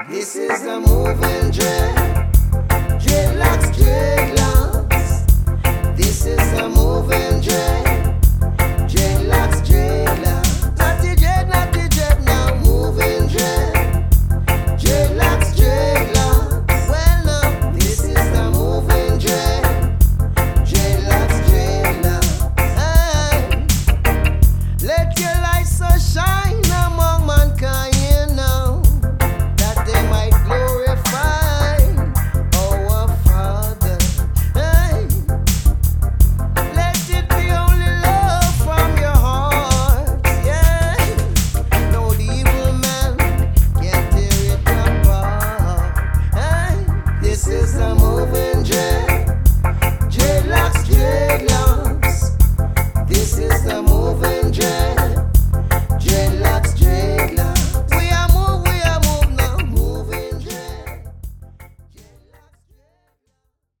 Roots singer
on a dubby rhythm track